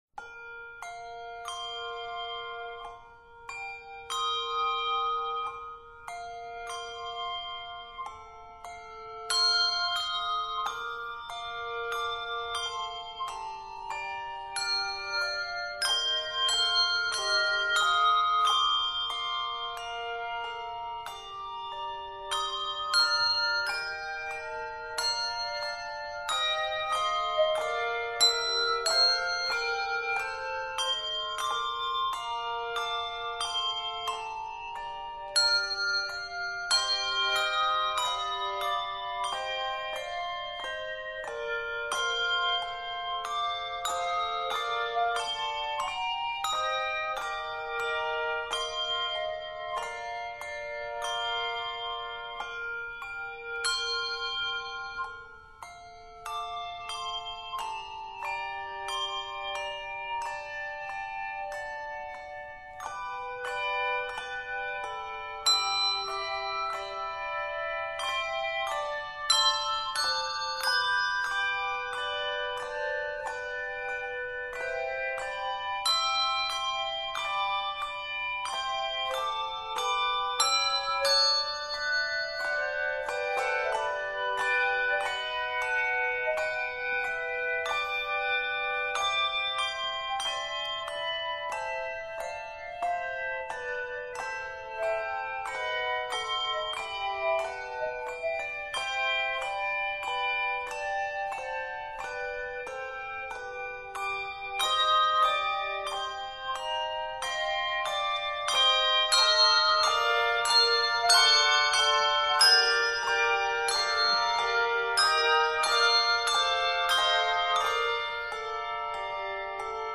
This is a wonderful meditative arrangement
Scored in Bb and G Majors, this piece is 63 measures.